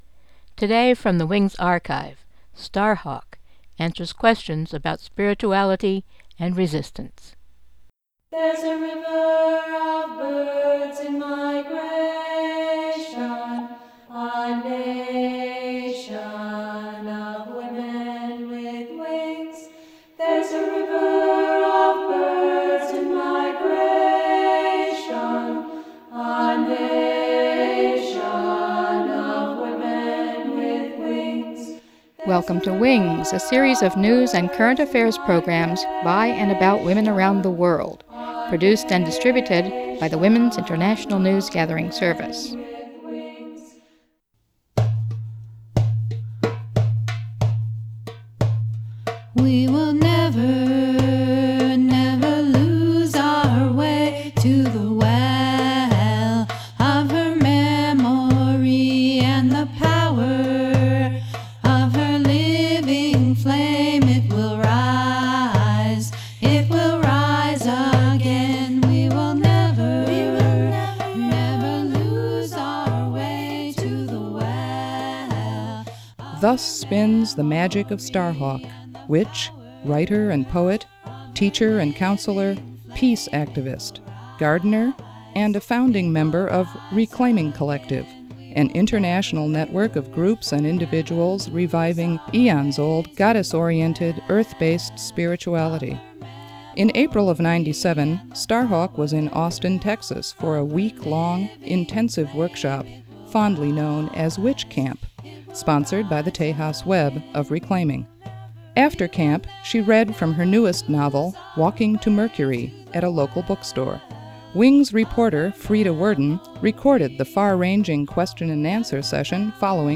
Starhawk recorded 1997 at a book launch in Austin, Texas